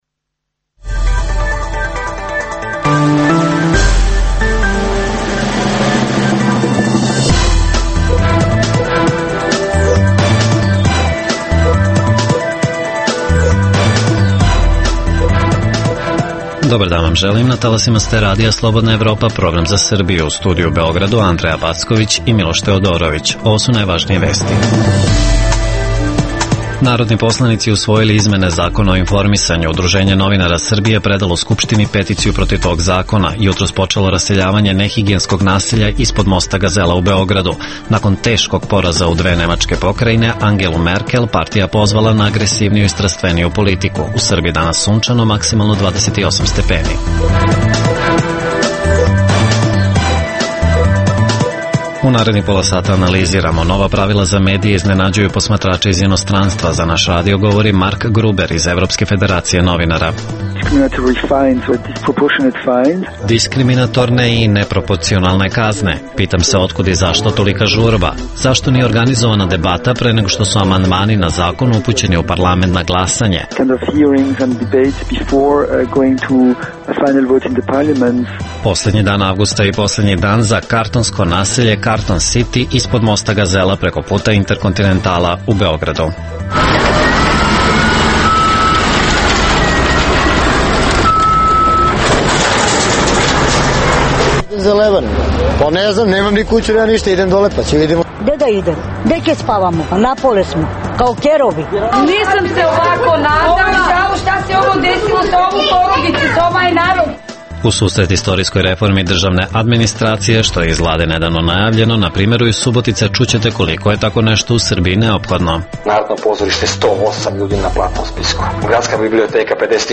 Takođe, sa lica mesta izveštavamo o poslednjem danu „karton sitija“, čuvenog nehigijenskog naselja ispod mosta Gazela u Beogradu čije je raseljavanje jutros počelo. Govorimo o nužnosti redukcije državne administracije, obeležavanju Dana nestalih u BiH, kao i turskoj ekspanziji na polju trgovine energentima.